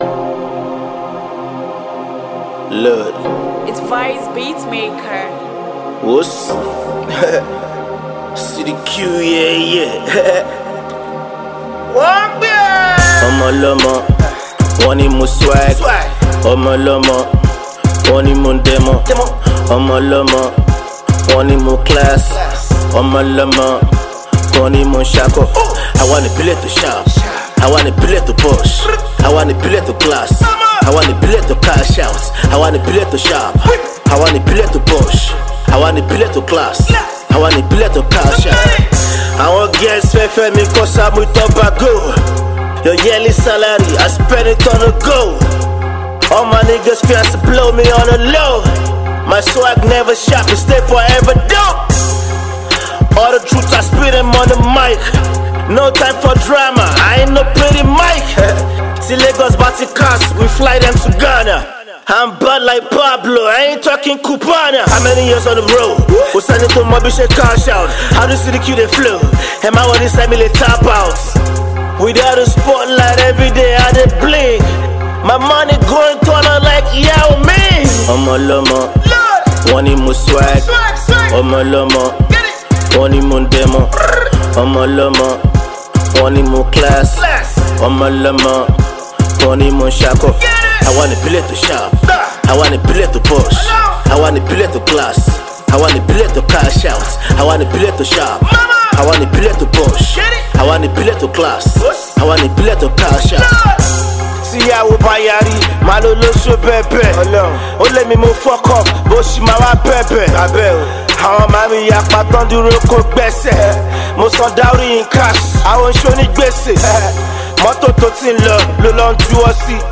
Nigerian rapper, singer and songwriter